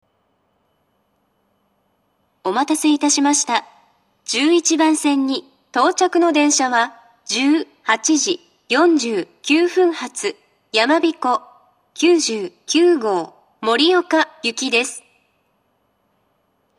２０２１年１月下旬頃には、自動放送が合成音声に変更されました。
１１番線到着放送